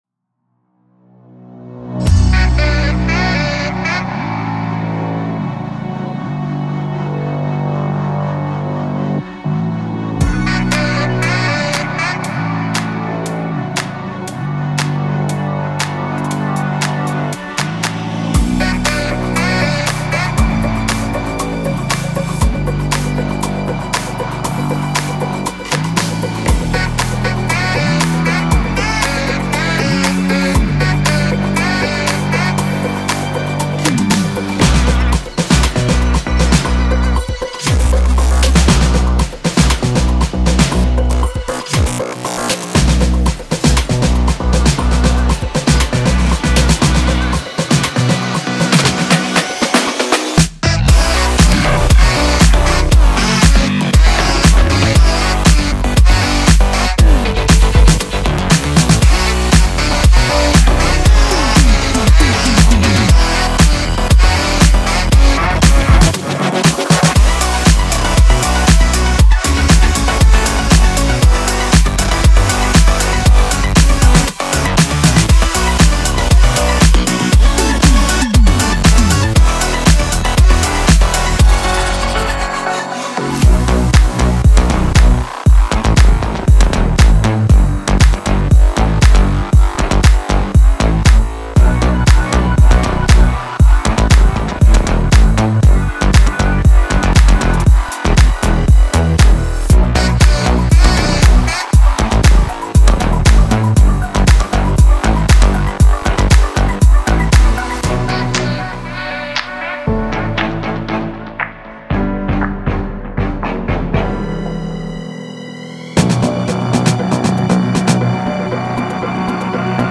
Saxophone and EDM?
And voilà—the track I was destined to create was heavily inspired by the saxophone taking part as the lead melody instead of a synth.
In the making of this track, I managed to find a pretty decent saxophone mixer for the digital audio workstation (DAW) that I use: adding some neat filters over it gave an awesome sound, creating the track below!
sax-edm.mp3